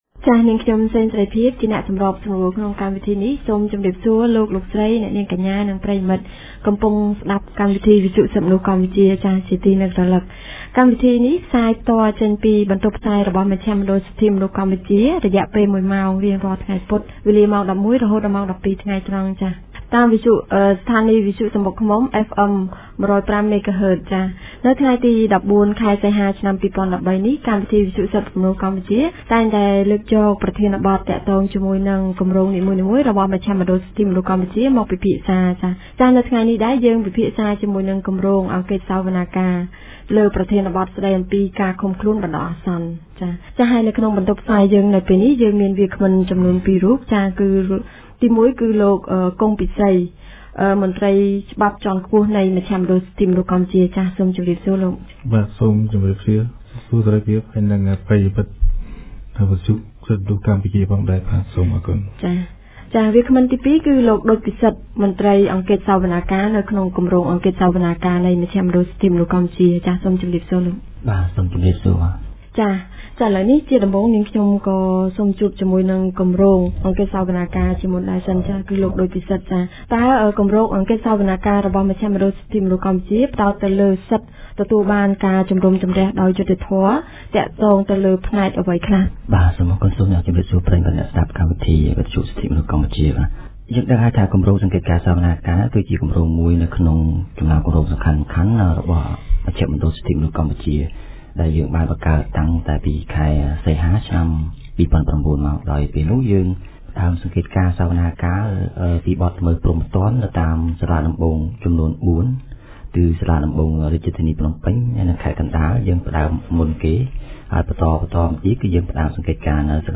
On 14 August 2013, TMP held a radio show addressing the use of pre-trial detention in Cambodian courts.